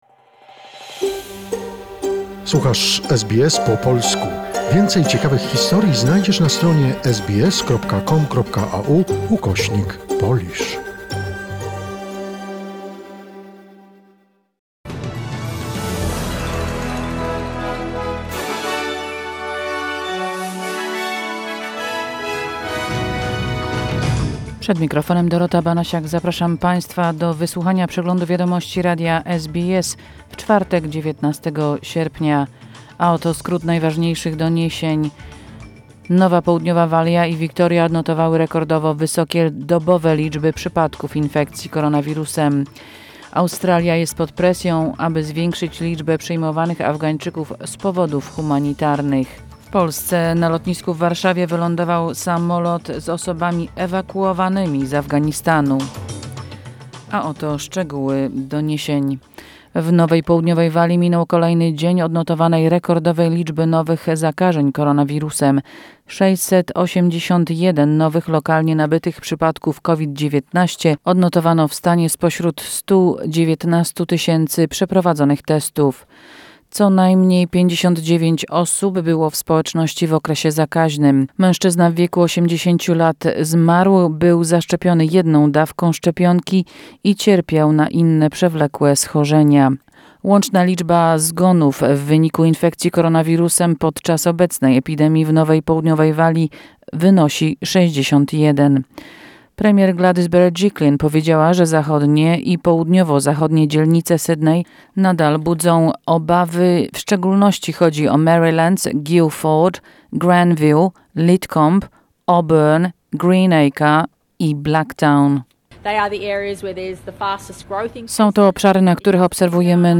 SBS News Flash in Polish, 19 August 2021